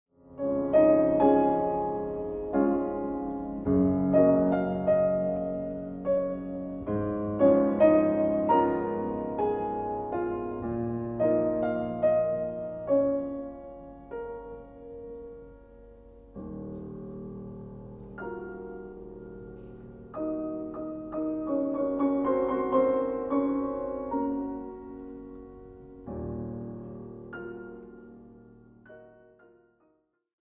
Ascolta un frammento dal Clair de lune per pianoforte: Debussy_clair_de_lune.mp3